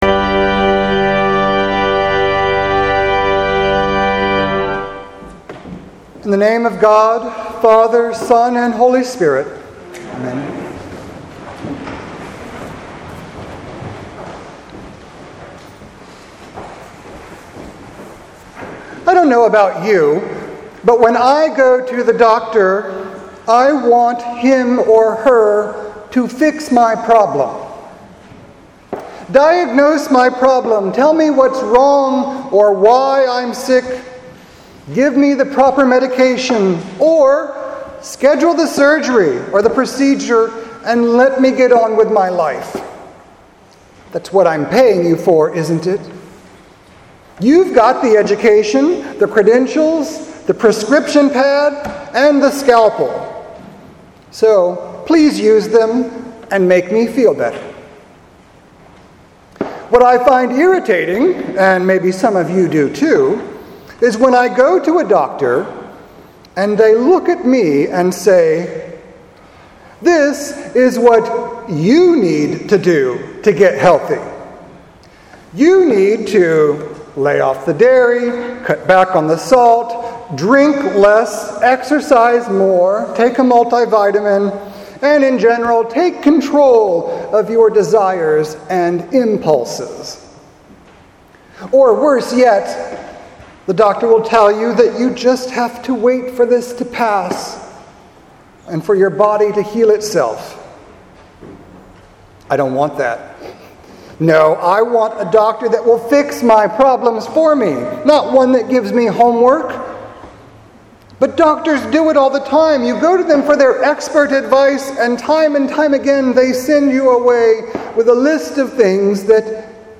Sermon for September 30th, 2018